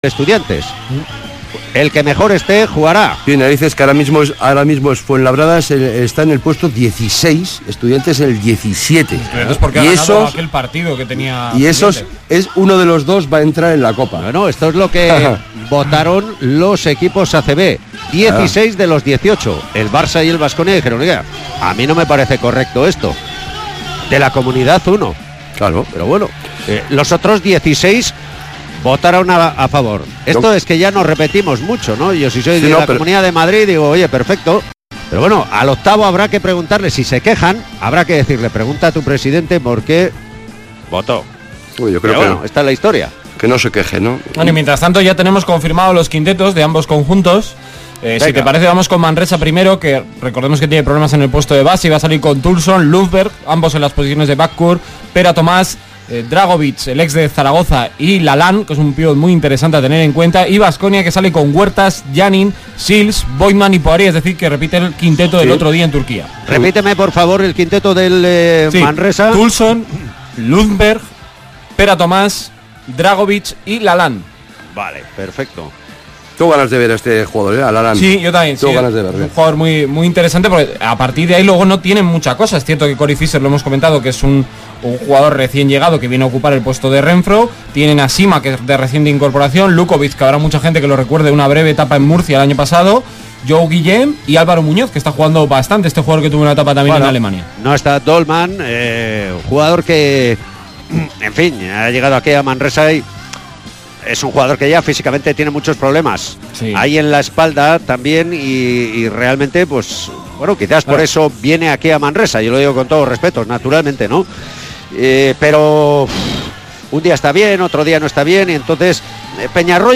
Baxi Manresa-Kirolbet Baskonia jornada 15 ACB 2018-19 retransmisión completa Radio Vitoria